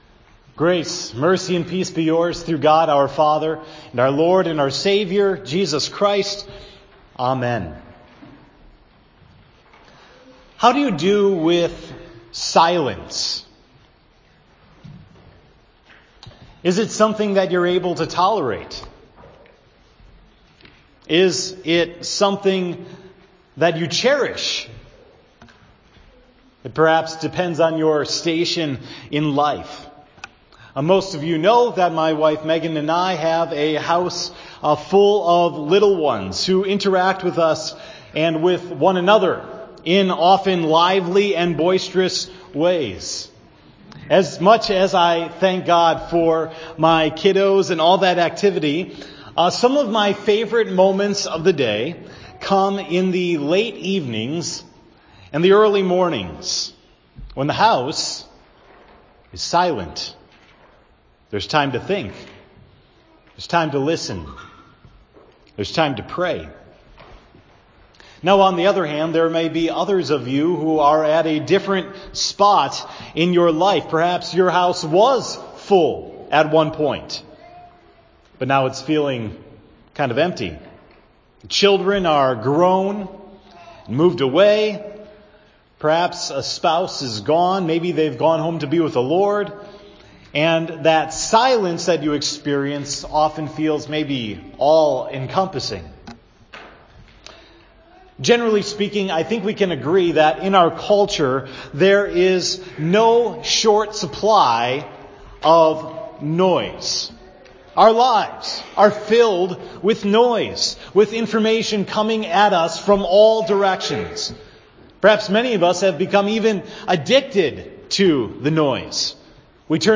“The Rest We Share” – Sermon for 10/14